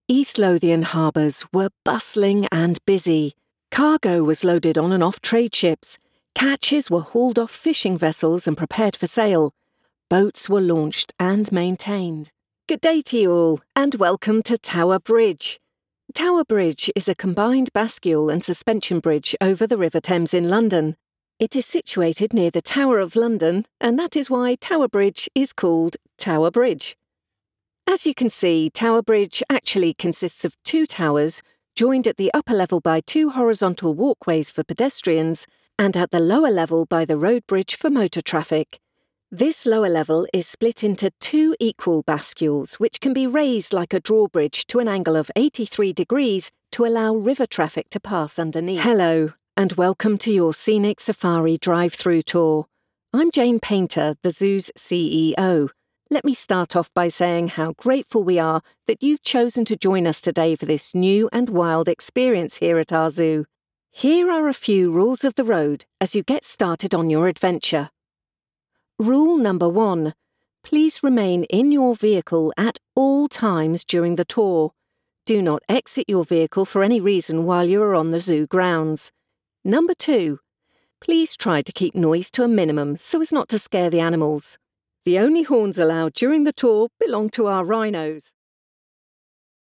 Juguetón
Elegante
Amistoso